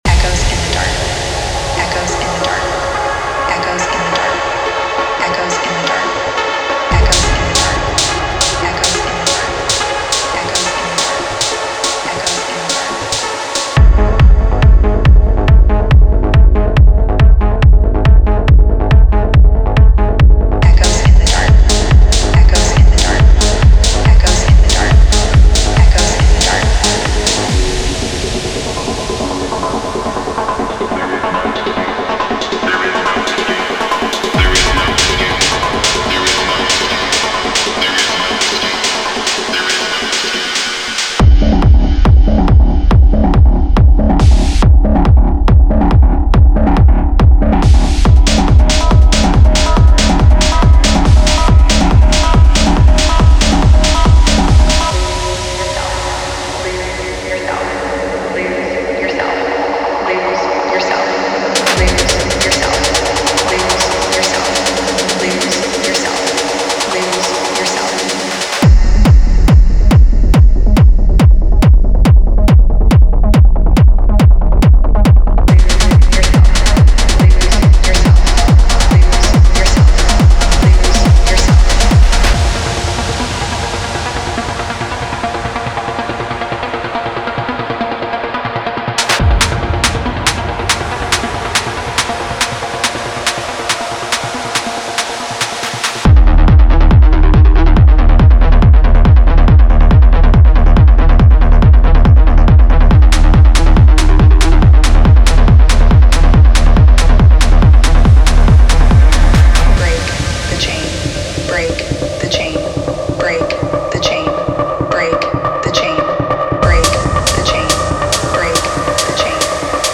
アンダーグラウンドなサウンドスケープが持つ、ダークで催眠的な可能性にアクセスしましょう。
デモサウンドはコチラ↓
Genre:Techno
140 bpm